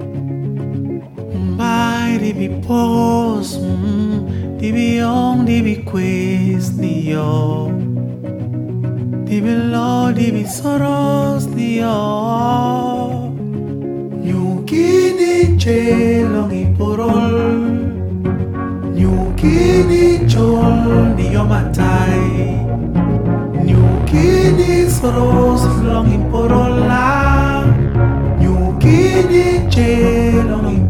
musique africaine : Cameroun
Musique du Monde